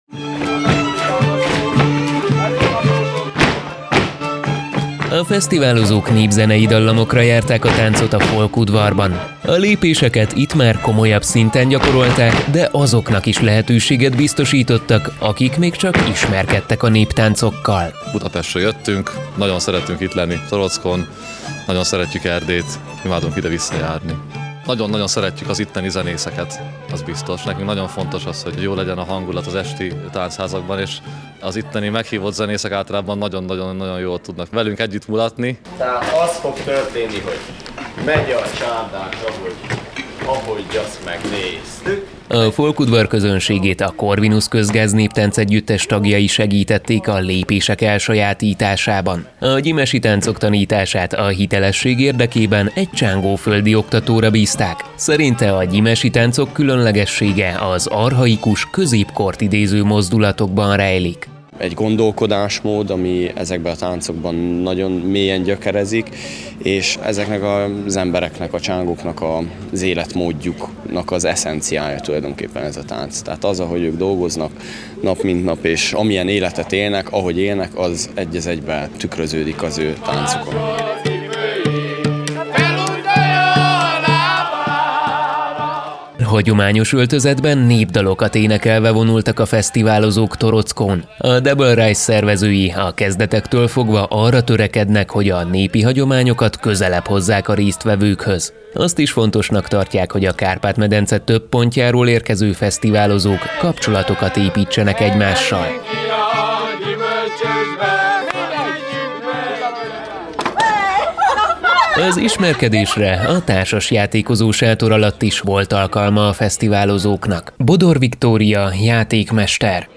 Jubileumiát ünnepelte idén a torockói Double Rise fesztivál.